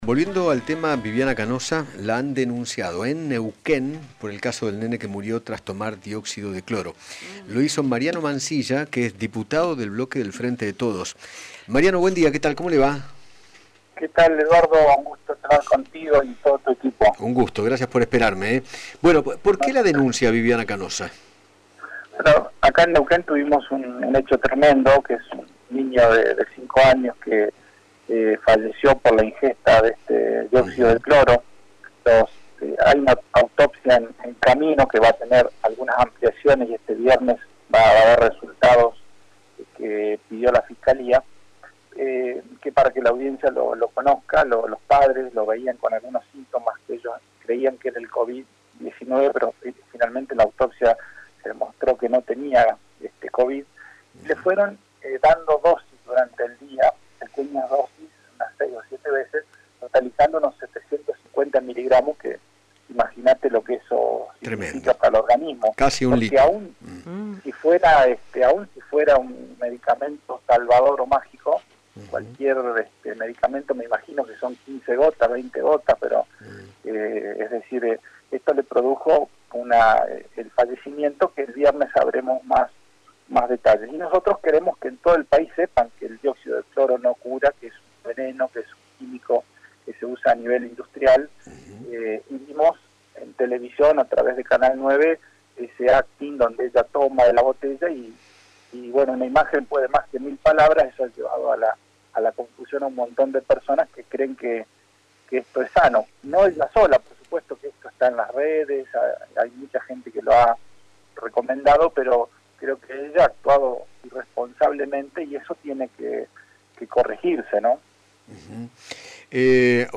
Mariano Mansilla, diputado provincial de Neuquén por el Frente de Todos, dialogó con Eduardo Feinmann sobre la denuncia penal que realizó en contra de la conductora Viviana Canosa por “recomendar” la ingesta de dióxido de cloro.